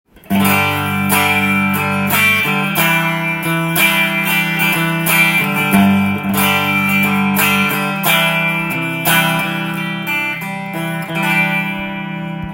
試しにこのギターを弾いてみました
リアのクリーントーンがこの音色です。ジャキジャキした音の中に艶があり
まさに高級ギターの音色。１弦から６弦までのバランスも最高です。